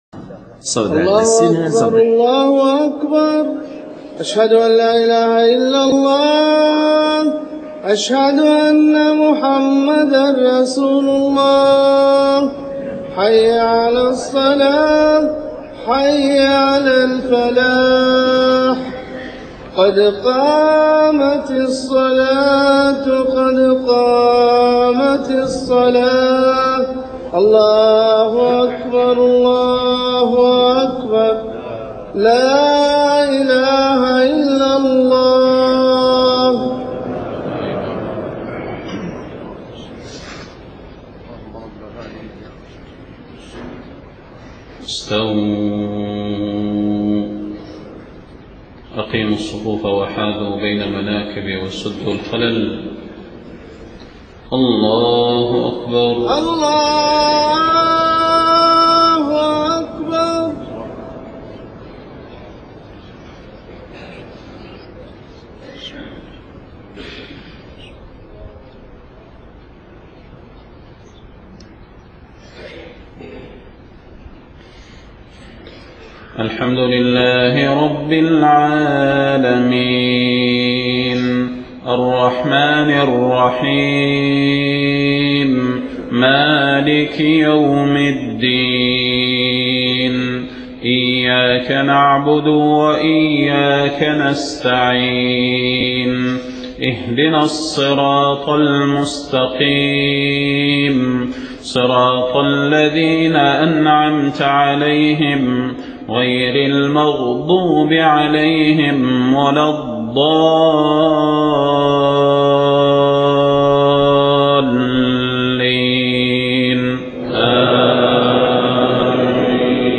صلاة الفجر 4 صفر 1430هـ سورتي السجدة والإنسان > 1430 🕌 > الفروض - تلاوات الحرمين